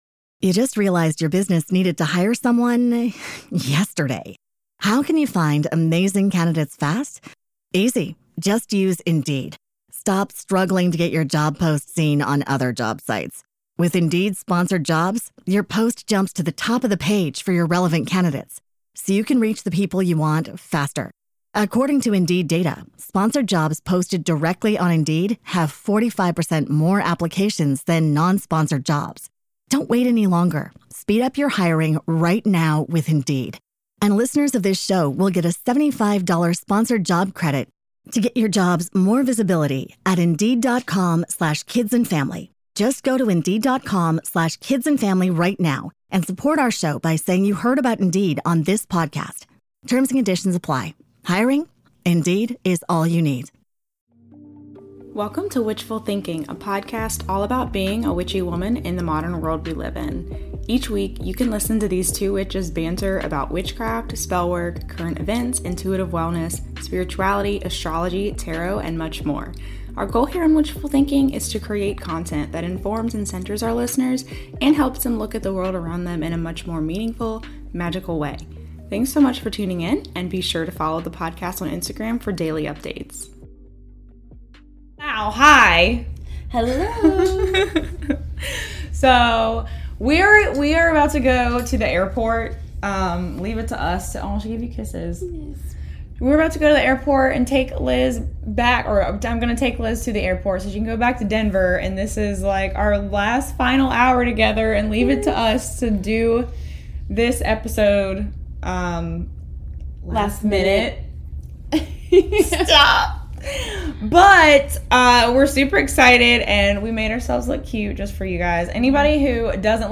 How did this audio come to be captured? It's the long-awaited episode: WE'RE TOGETHER IN PERSON! The video has tons of extra clips of us together in it, and the audio on all podcasting platforms has an extra 14 minute clip at the end of us talking sh*t on the beach and just enjoying life.